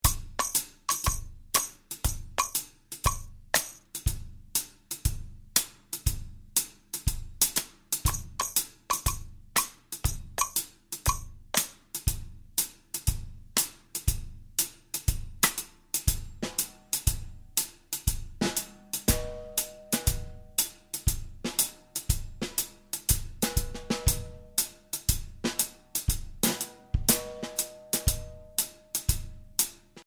Voicing: Jazz Keyboard